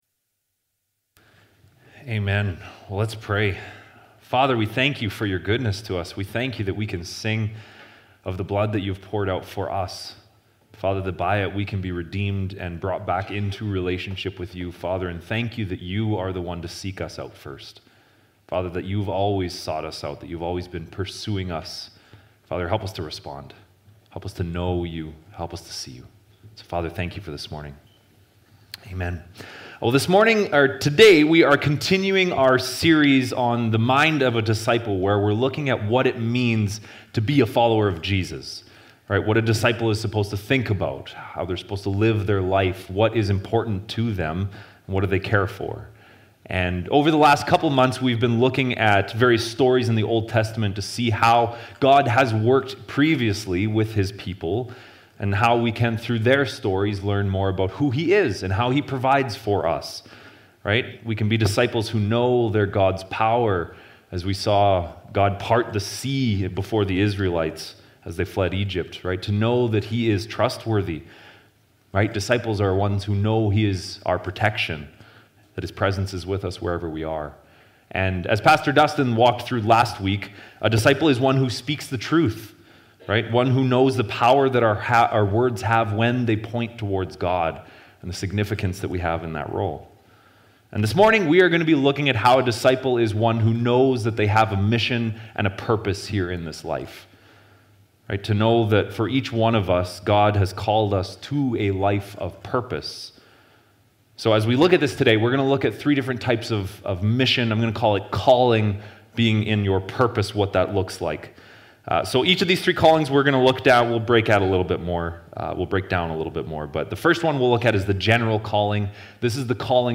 Sermons | Leduc Fellowship Church